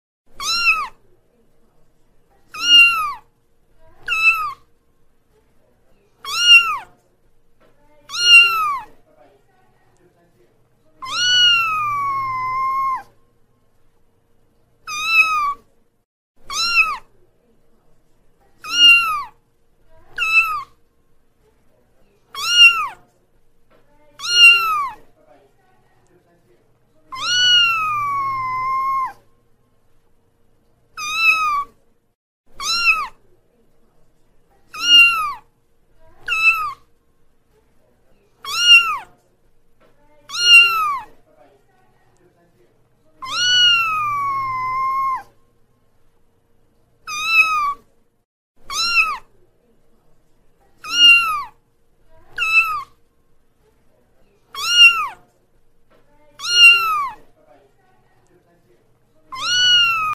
Tiếng Mèo Con kêu MP3 (Dễ Thương, Cute)